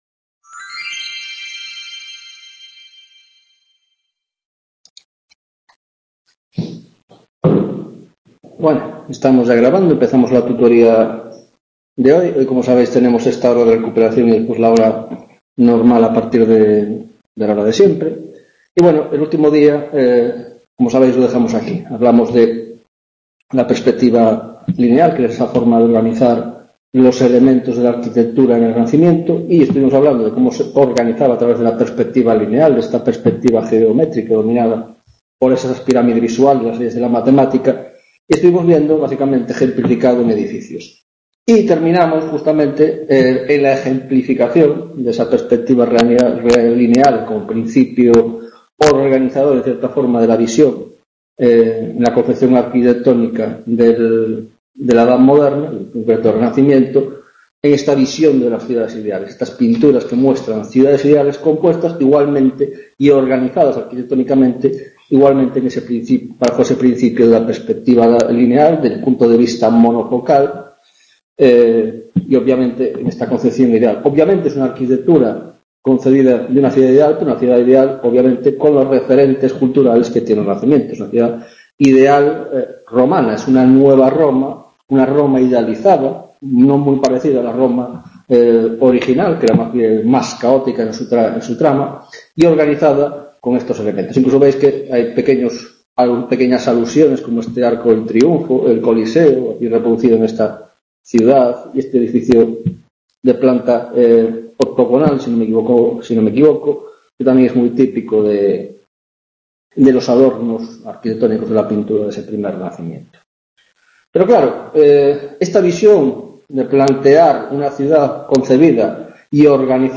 2ª Tutoría de Órdenes y Espacio en la Arquitectura del XV - XVIII - Introducción: La Concepción del Espacio 2